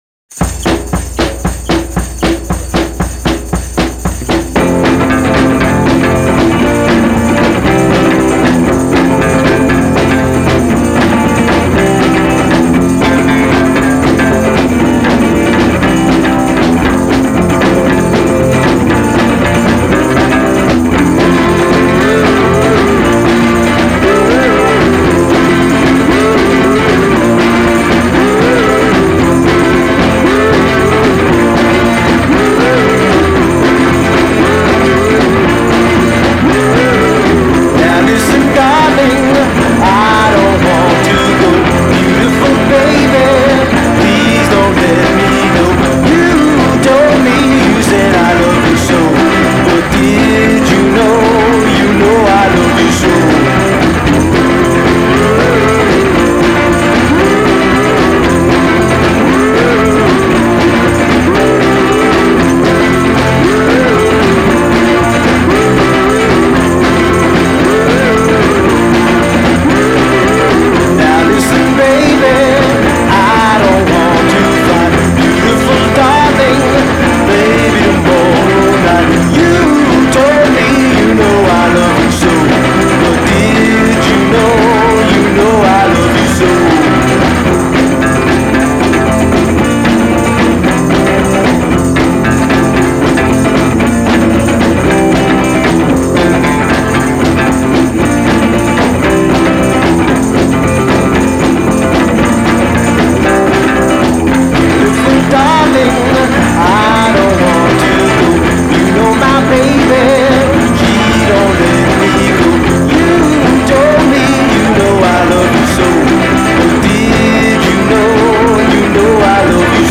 Новая музыка 2022Зарубежные новинки 2025Рок